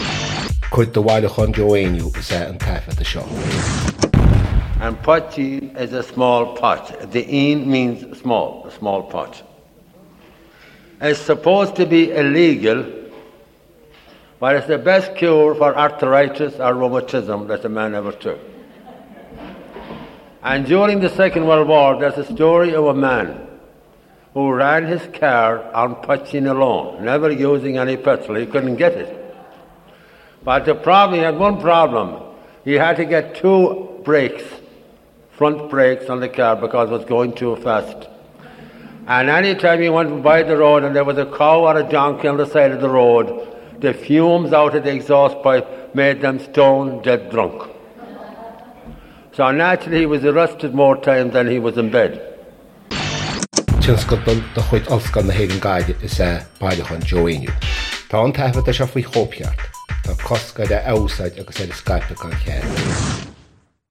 • Catagóir (Category): story.
• Ainm an té a thug (Name of Informant): Joe Heaney.
• Suíomh an taifeadta (Recording Location): Devon, Pennsylvania, United States of America.
• Ocáid an taifeadta (Recording Occasion): concert.